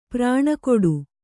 ♪ prāṇa koḍu